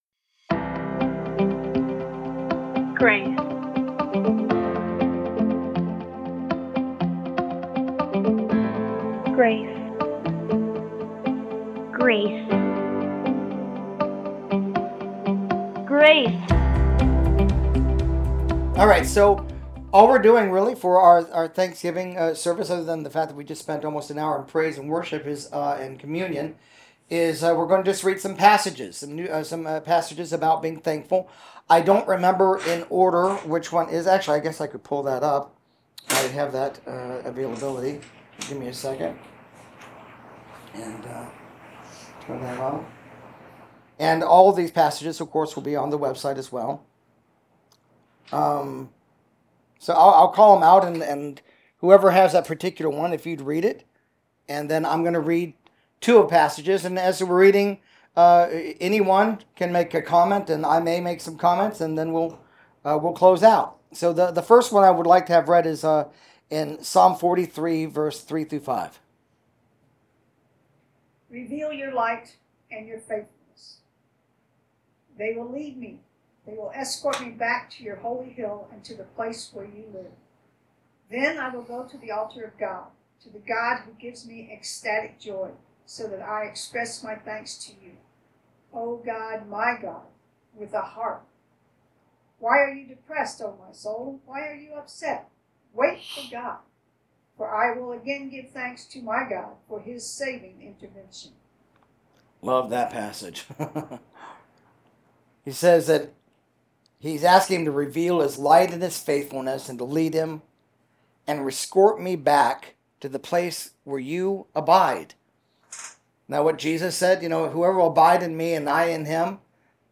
Message – Thanksgiving Celebration Service 2021